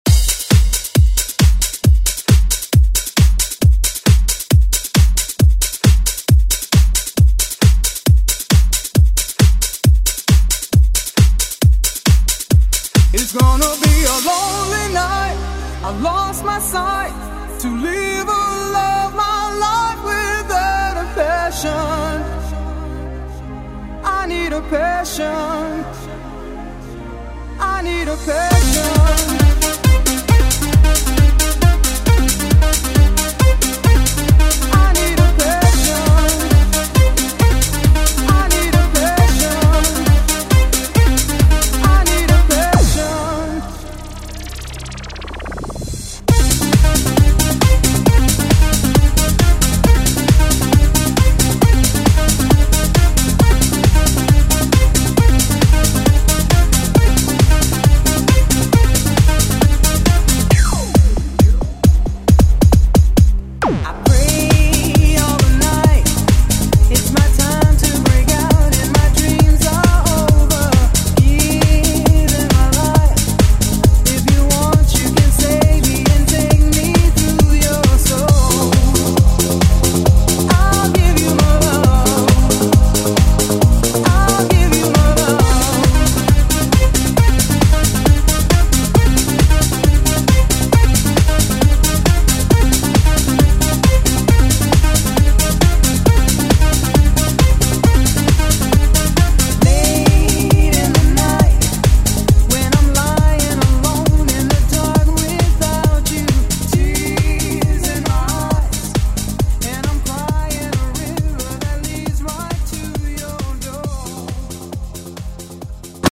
Throwback Electronic Pop Music Extended ReDrum Clean Version
Genre: 80's
Clean BPM: 119 Time